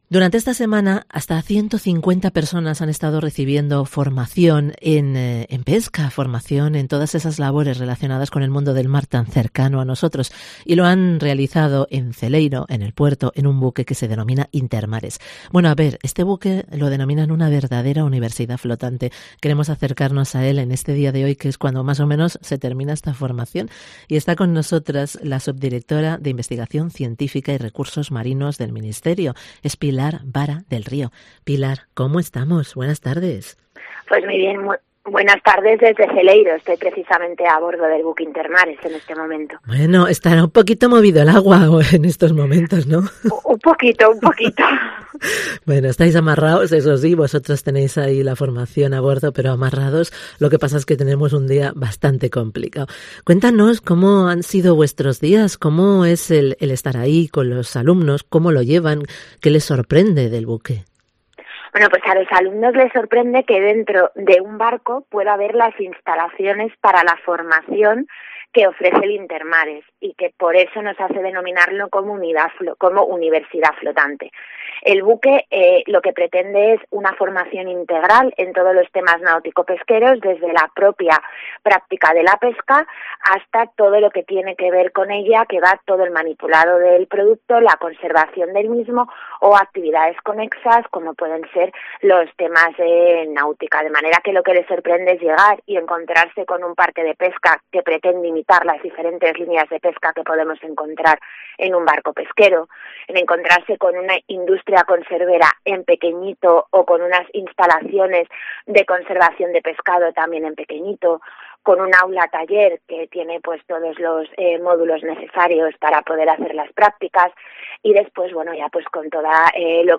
A bordo del buque Intermares, del Ministerio de Defensa, atracado en el Puerto de Celeiro